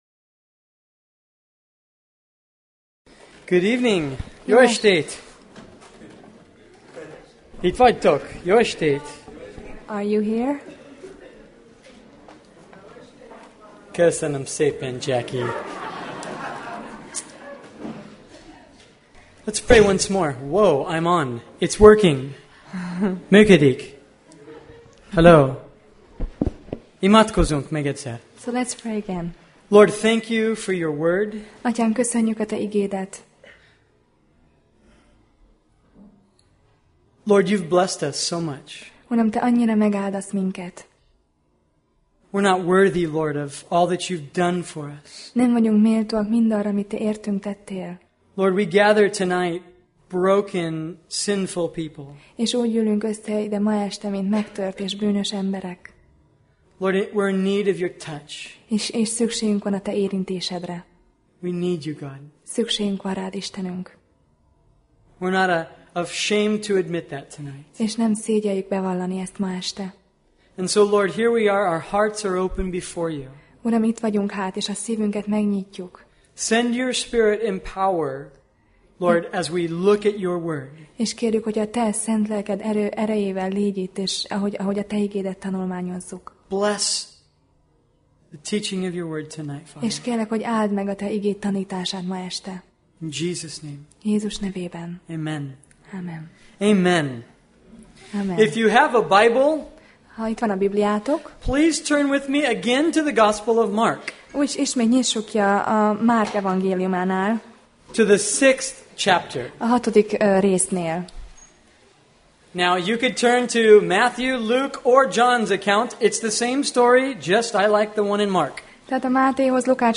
Sorozat: Tematikus tanítás Passage: Márk (Mark) 6:30-44 Alkalom: Szerda Este